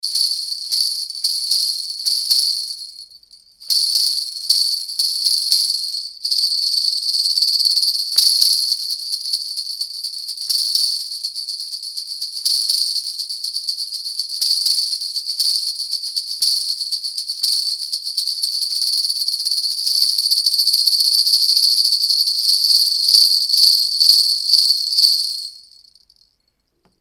Hochet 12 grelots
Montés sur des ressorts et fixés sur un bambou, ces 12 grelots vibreront au moindre mouvement que vous donnerez au support où ils sont fixés.
Stick-bells.mp3